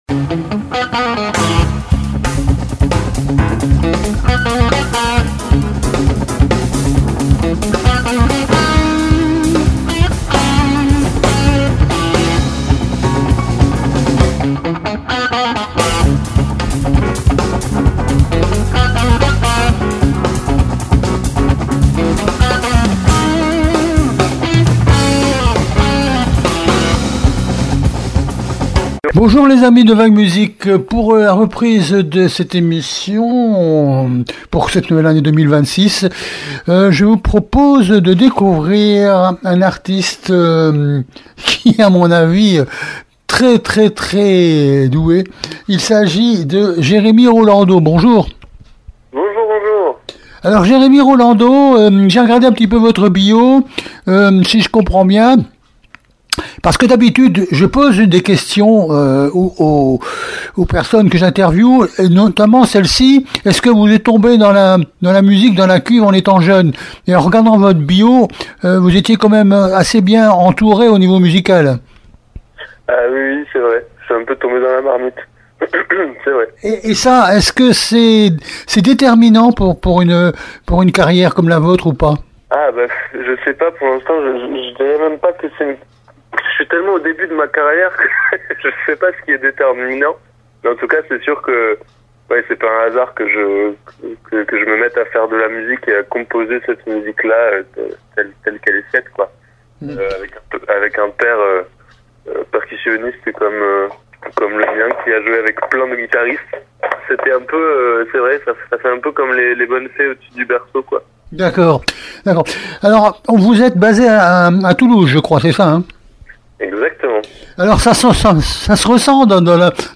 INTERVIEW DU 19 JANVIER 2026